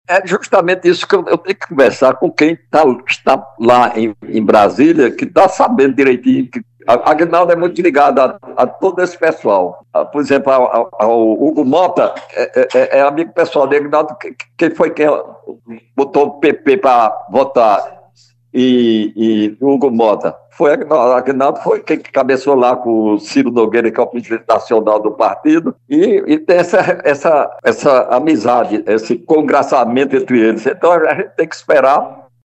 Os comentários do dirigente foram registrados pelo programa Correio Debate, da 98 FM, de João Pessoa, nesta quinta-feira (09/01).